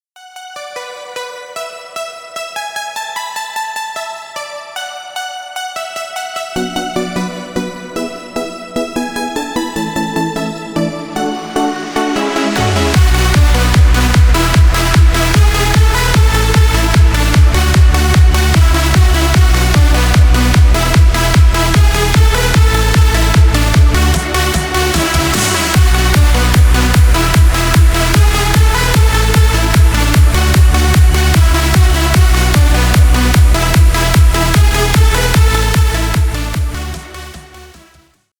Ремикс # Электроника
без слов # клубные